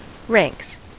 Ääntäminen
Ääntäminen US Haettu sana löytyi näillä lähdekielillä: englanti Ranks on sanan rank monikko.